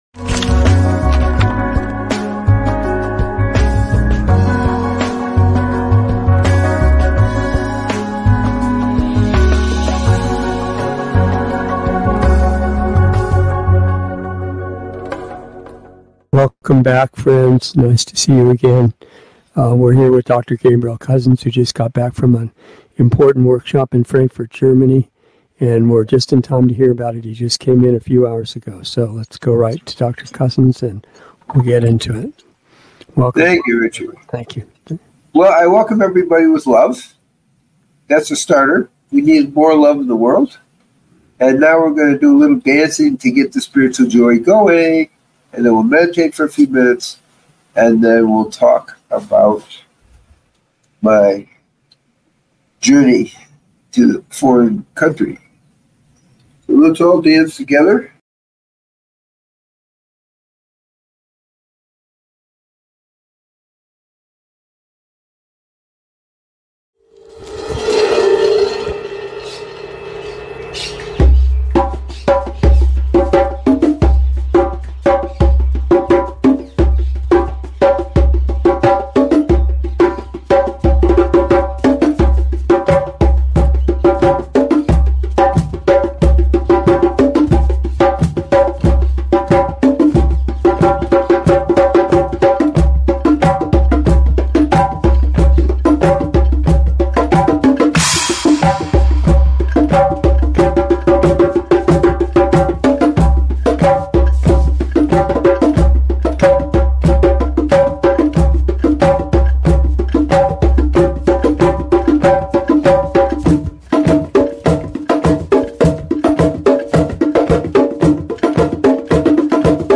A new LIVE series
Dialogs